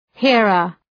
Προφορά
{‘hırər}